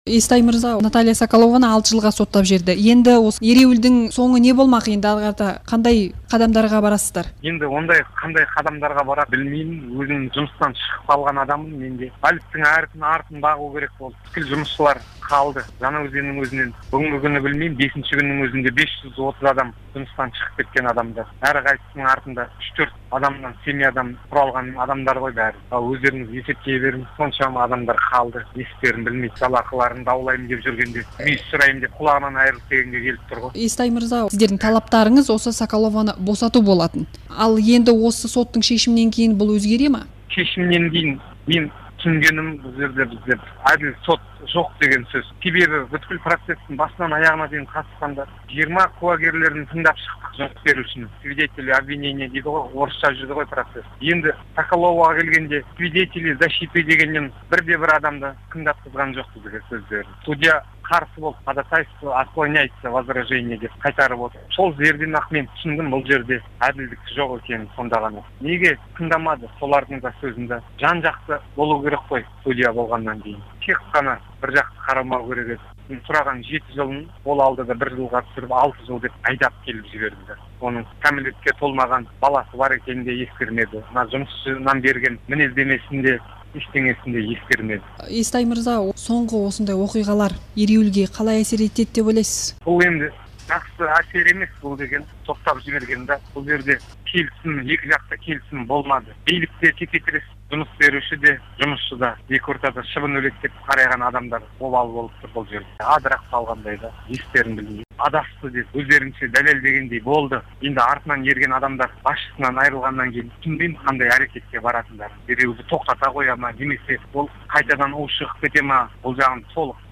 сұқбат